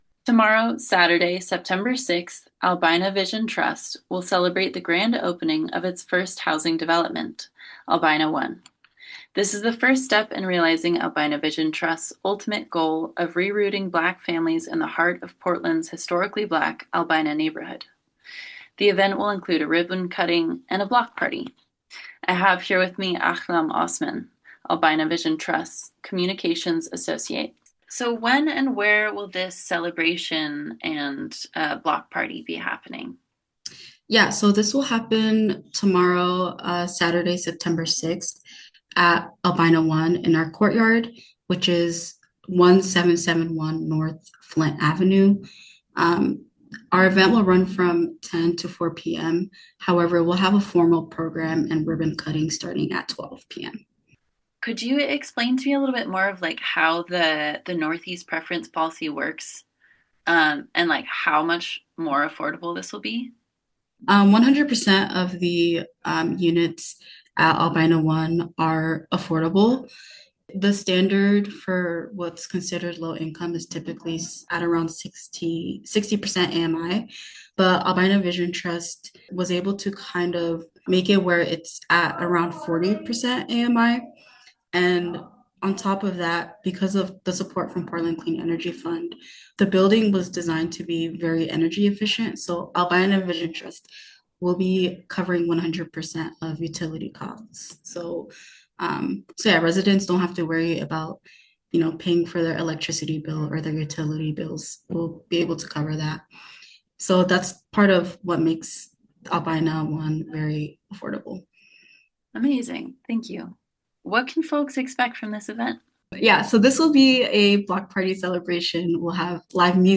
Evening News
0905_or_albina_ribbon_cutting.mp3